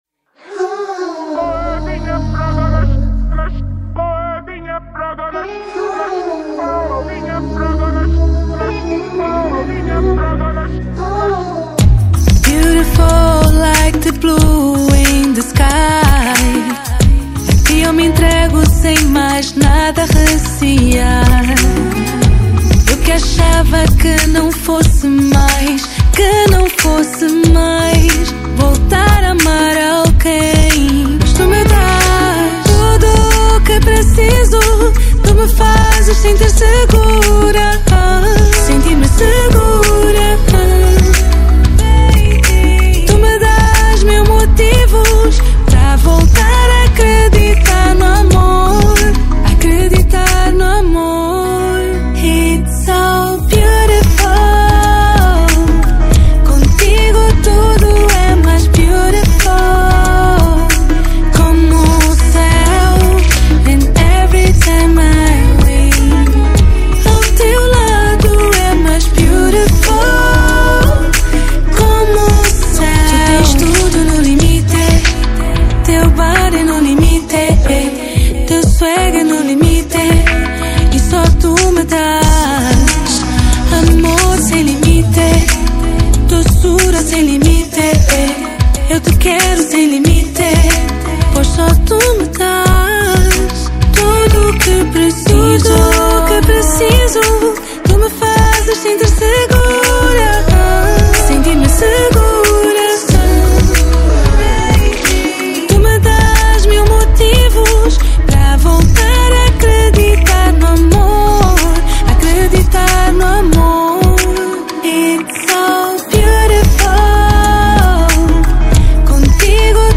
Estilo: R&b